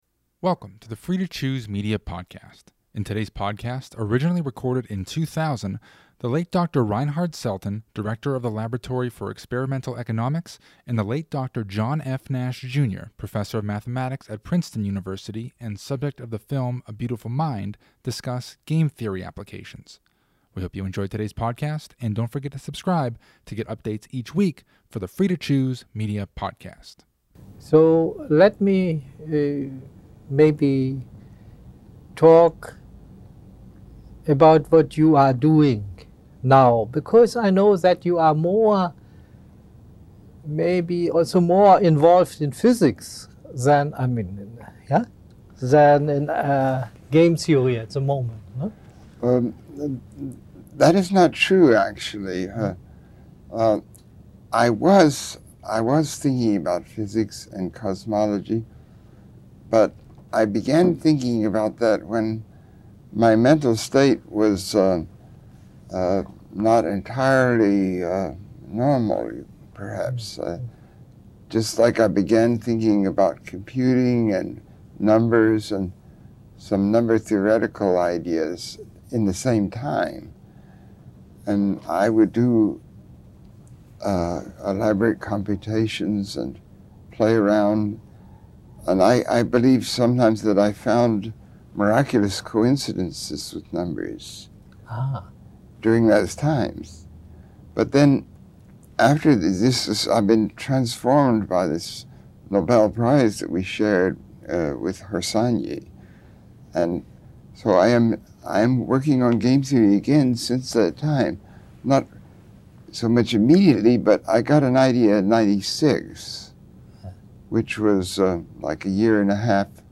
Listen to the late Dr. Reinhard Selten, Director of the Laboratory for Experimental Economics and the late Dr. John F. Nash, Jr., Professor of Mathematics at Princeton University, discuss Game Theory Applications.
This discussion was recorded in 2000.